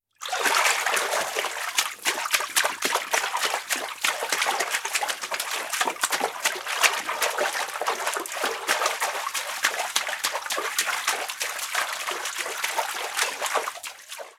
Movimientos de alguien ahogándose en una charca